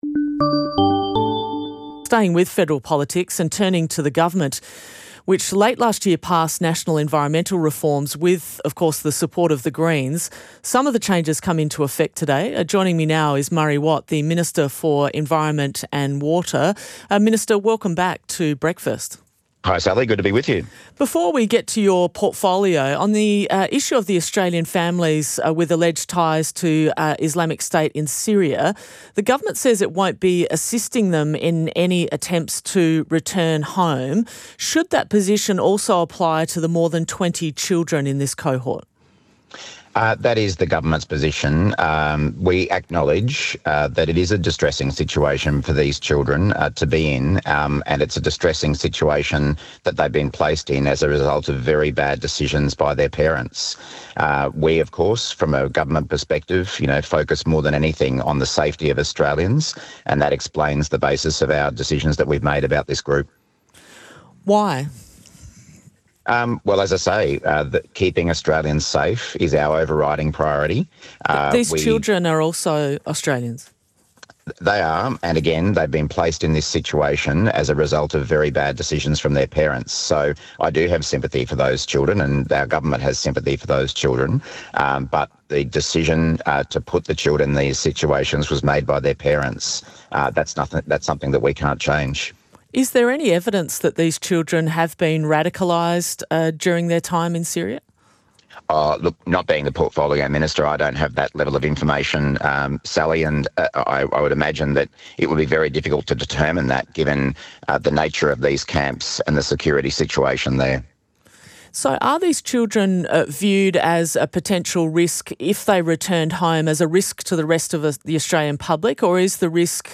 GUEST: Murray Watt, Minister for the Environment and Water PRODUCER